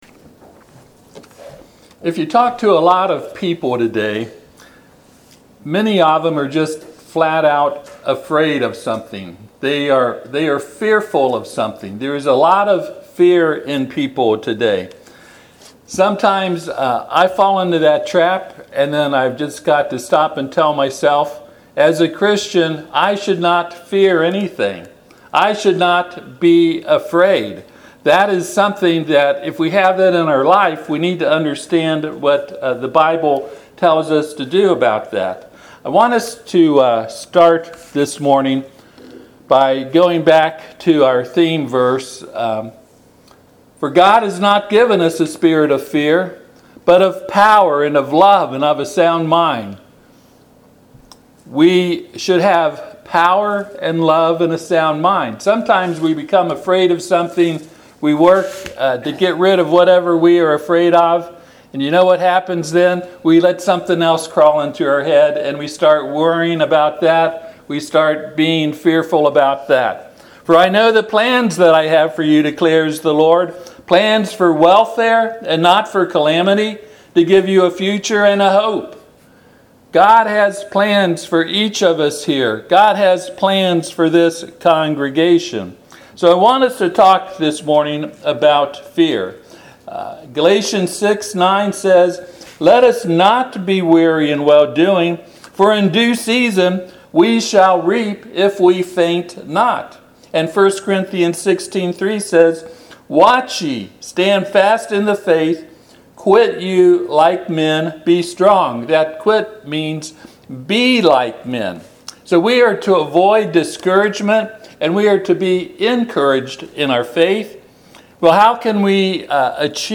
Passage: Joshua 1:8-9 Service Type: Sunday AM